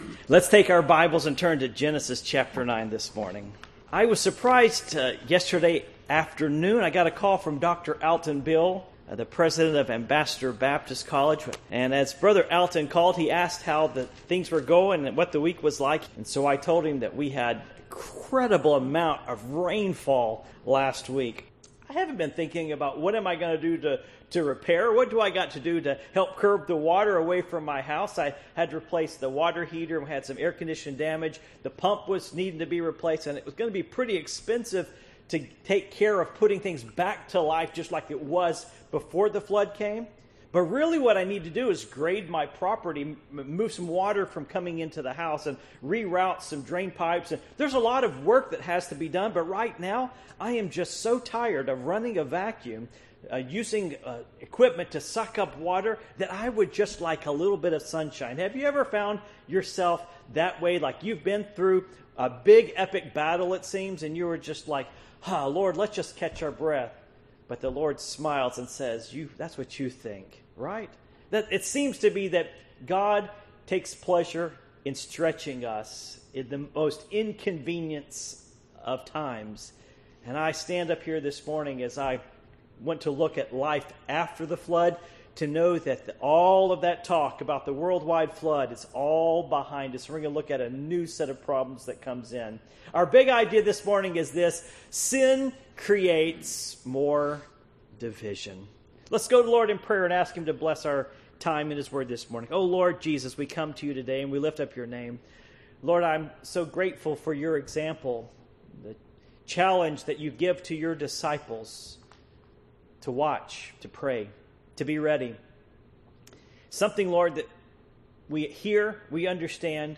Series: The Ministry of the Encourager Passage: Genesis 9:18-29 Service Type: Morning Worship